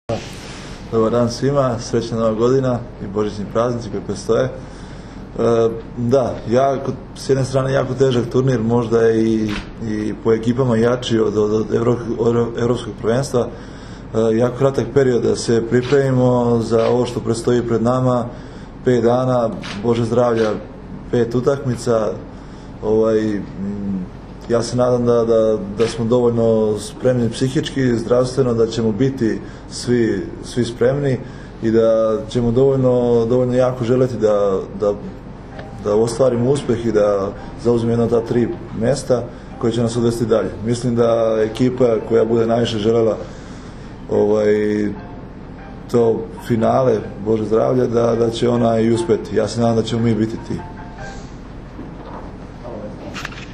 Tim povodom danas je u beogradskom hotelu “M” održana konferencija za novinare, kojoj su prisustvovali Nikola Grbić, Dragan Stanković, Marko Ivović i Uroš Kovačević.
IZJAVA MARKA IVOVIĆA